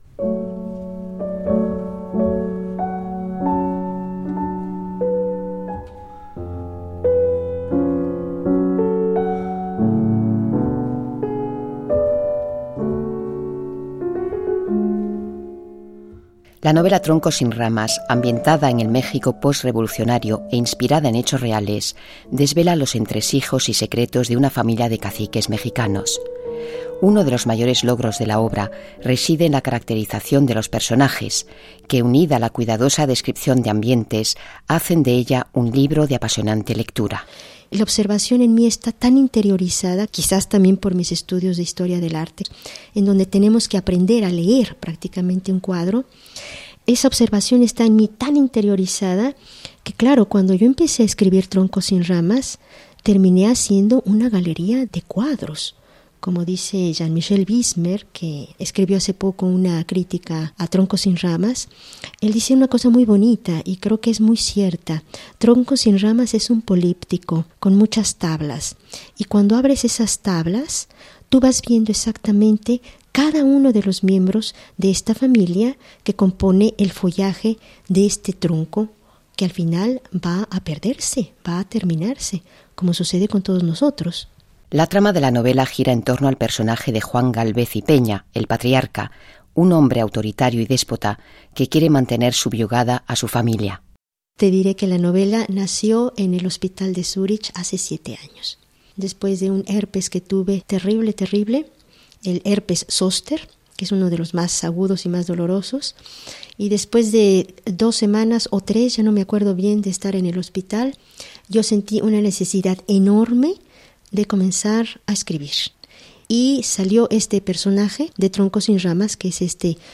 Música de trasfondo de Mozart y Massenet.